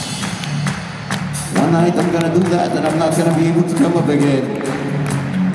Removing continuous, rhythmic clapping
The sound I am trying to remove is actually somewhat similar to wind or ocean waves crashing, so I also tried many different methods that seem to work for those things.
The sound is about the same, or higher, volume than the speaking, and thus it may be impossible to remove it.